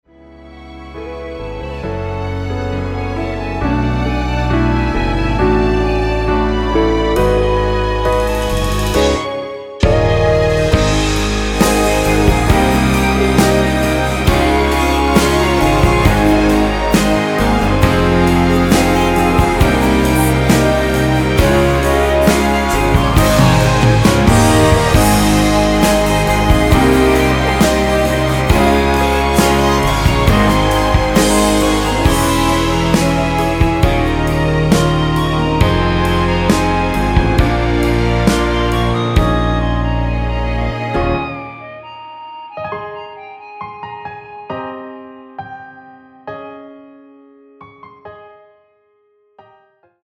이곡의 코러스는 미리듣기에 나오는 부분밖에 없으니 참고 하시면 되겠습니다.
원키에서(+4)올린 멜로디와 코러스 포함된 MR입니다.(미리듣기 확인)
F#
앞부분30초, 뒷부분30초씩 편집해서 올려 드리고 있습니다.
중간에 음이 끈어지고 다시 나오는 이유는